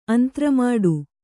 ♪ antramāḍu